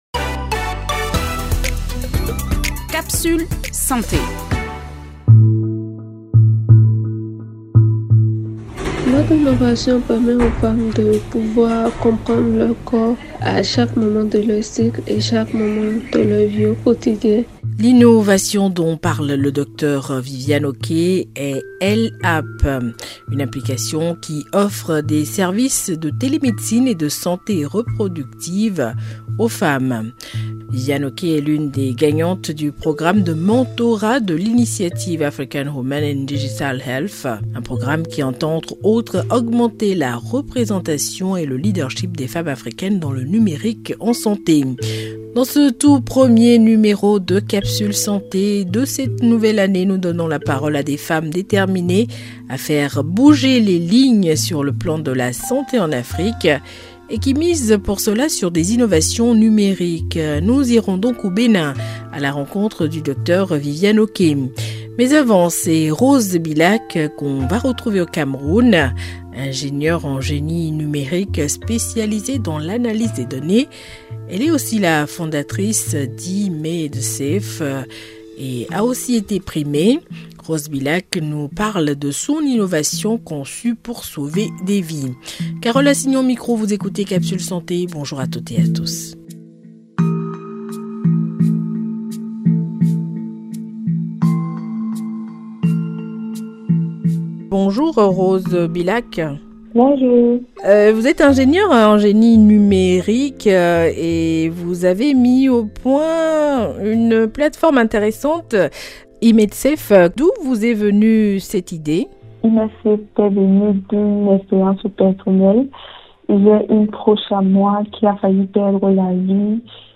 A l’aide de reportages et d’interviews avec des spécialistes, ce magazine propose une information concrète et vivante sur les grands thèmes de santé.